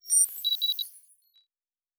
Sci-Fi Sounds / Electric / Data Calculating 2_2.wav
Data Calculating 2_2.wav